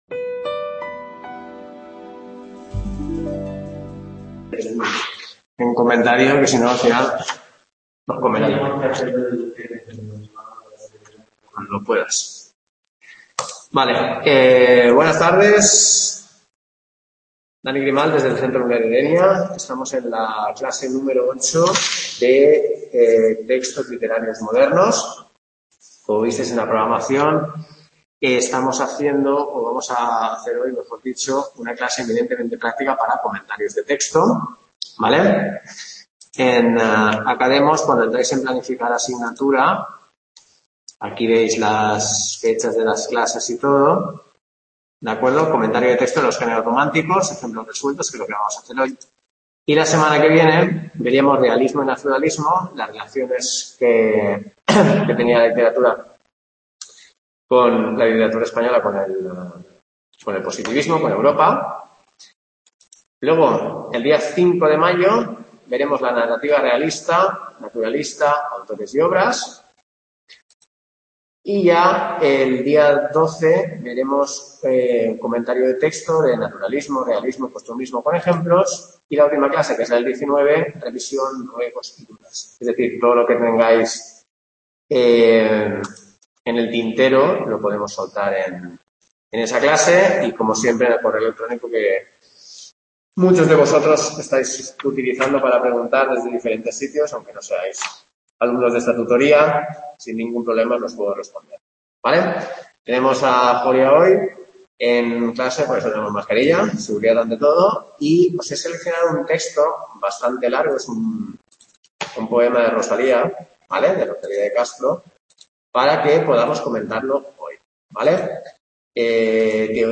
CLASE 8 COMENTARIO DE TEXTO, Textos modernos | Repositorio Digital